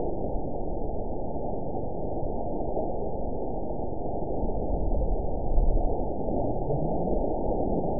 event 921257 date 05/06/24 time 02:53:09 GMT (1 week, 5 days ago) score 9.49 location TSS-AB04 detected by nrw target species NRW annotations +NRW Spectrogram: Frequency (kHz) vs. Time (s) audio not available .wav